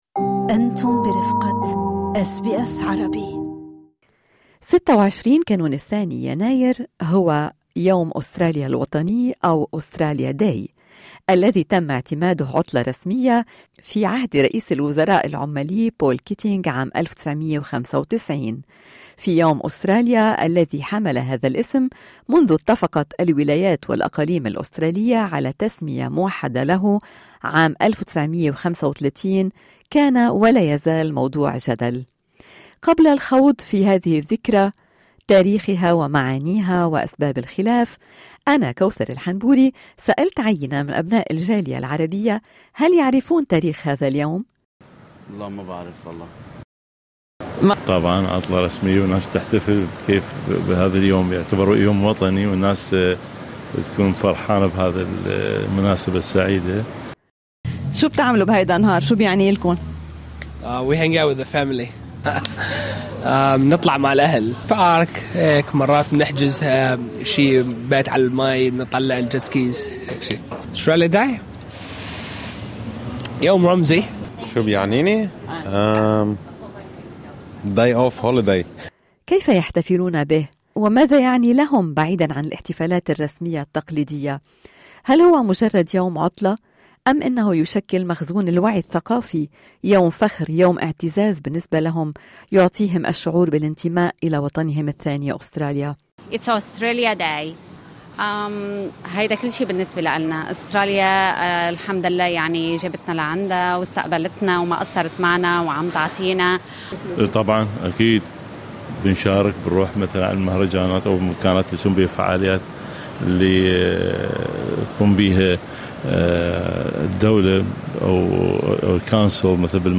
الأجابات ضمن التقرير الصوتي أعلاه: أكملوا الحوار على حساباتنا على فيسبوك و انستغرام.